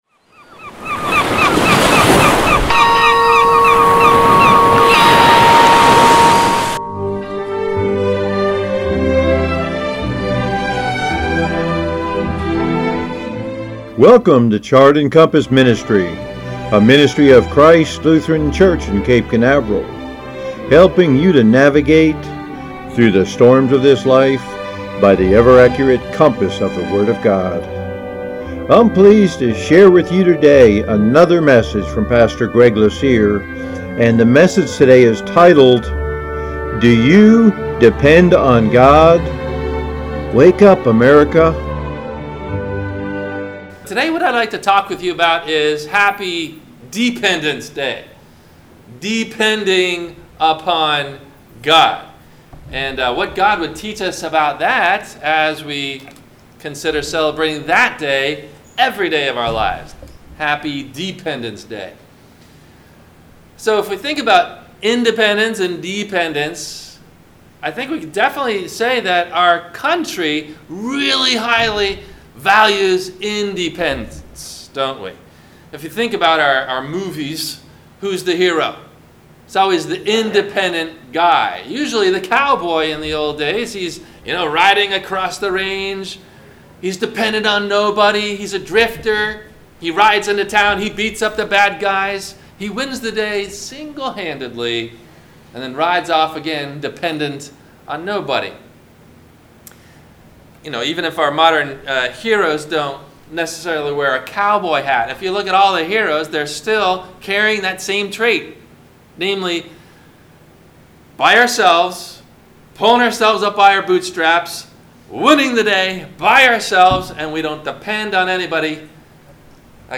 Do You Depend On God? : Wake Up America! – WMIE Radio Sermon – July 22 2019